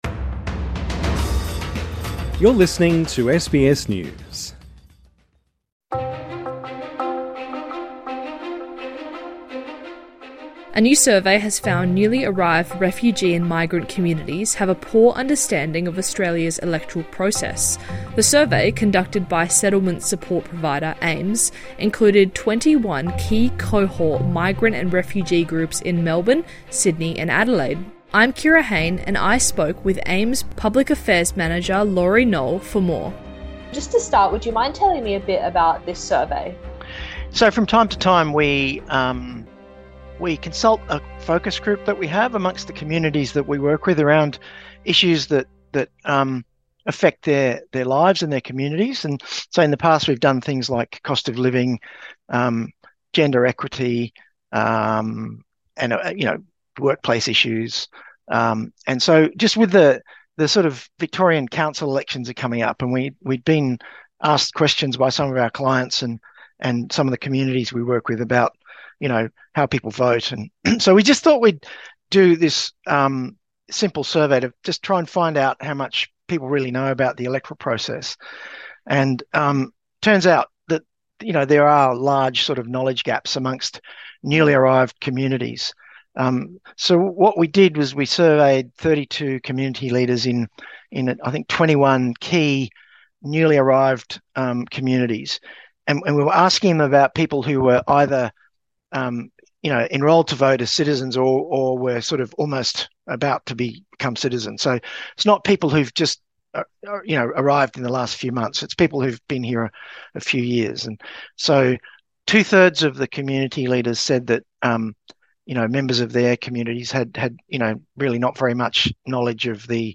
INTERVIEW: How well do migrant and refugee communities understand Australia's electoral process?